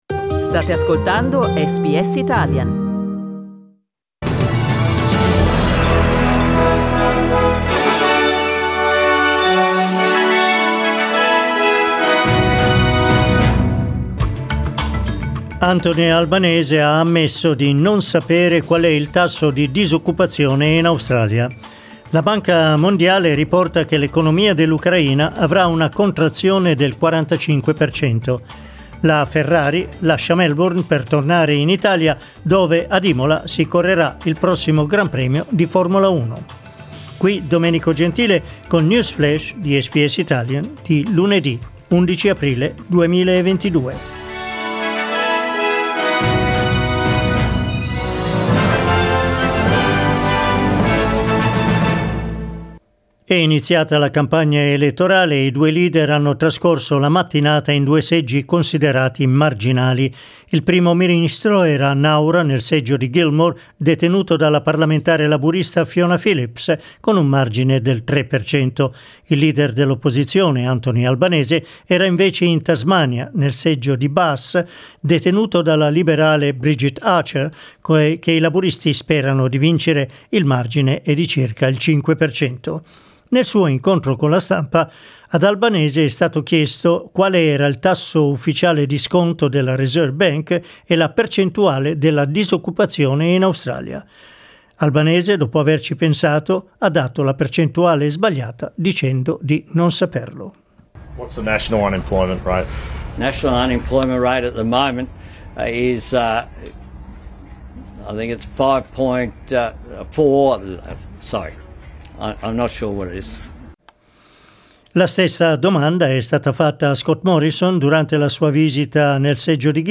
News flash lunedì 11 aprile 2022
L'aggiornamento delle notizie di SBS Italian.